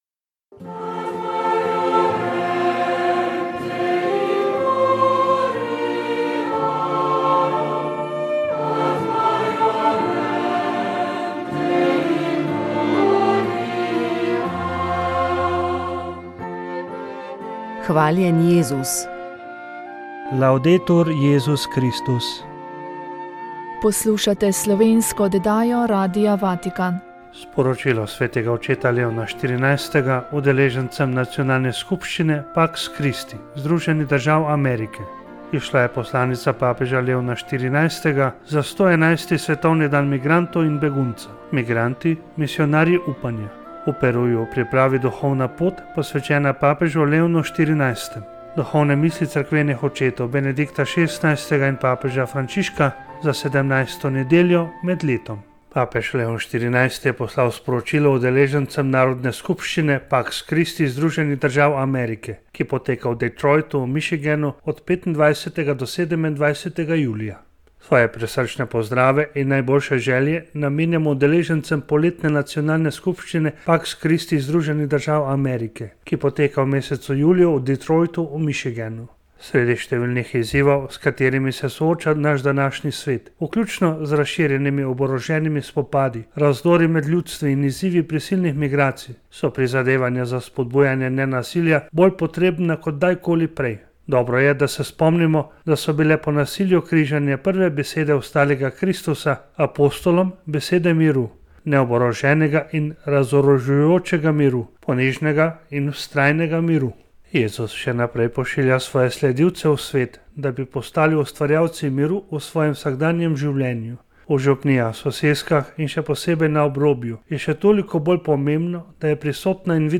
S predstavniki lokalne skupnosti, civilne iniciative in policije smo spregovorili o reševanju romske problematike na jugovzhodu države. Dotaknili smo se trenutnih varnostnih razmer, ukrepov v sklopu Šutarjevega zakona, dodatno predlaganih rešitev na področju socialne in delovne aktivacije ter pričakovanj različnih akterjev.